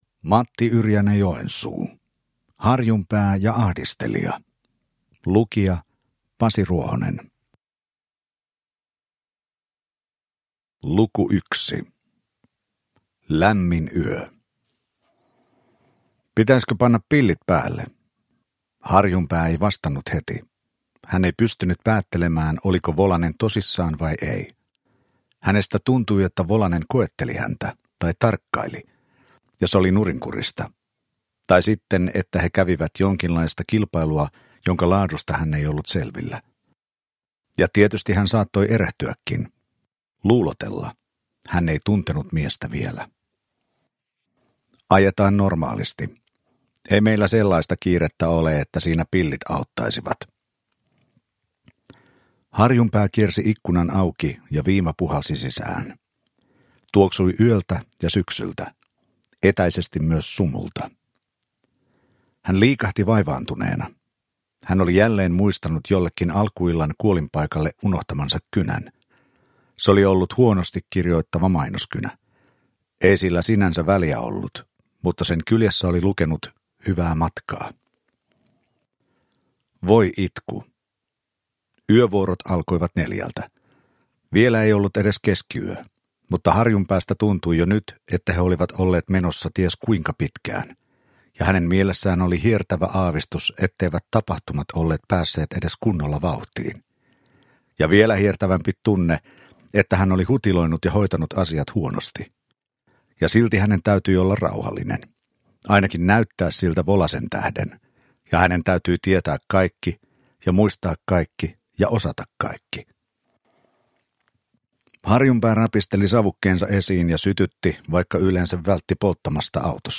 Harjunpää ja ahdistelija (ljudbok) av Matti-Yrjänä Joensuu